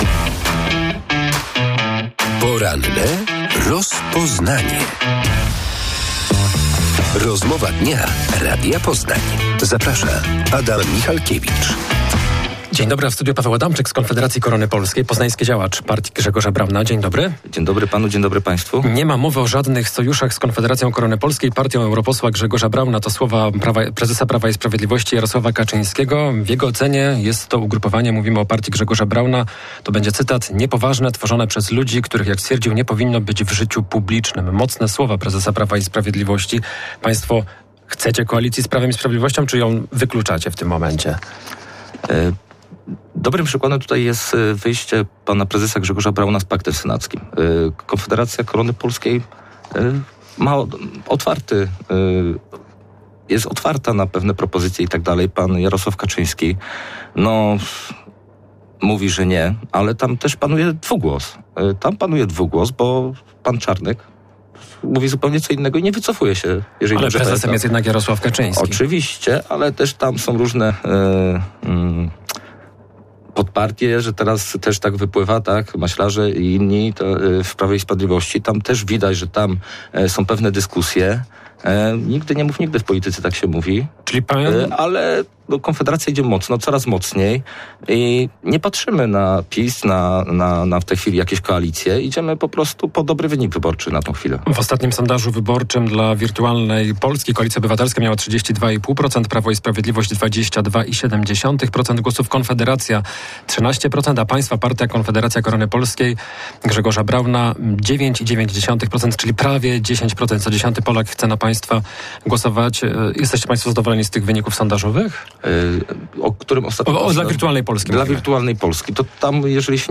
w porannej rozmowie Radia Poznań